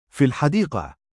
♪ 音声サンプル：下の「文語的発音（休止法）」に相当する読み方です
文語的発音（休止法*）：فِي الْحَدِيقَة [ fi-l-ḥadīqa(h) ] [ フィ・ル＝ハディーカ ]